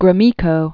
(grə-mēkō, grō-), Andrei Andreyevich 1909-1989.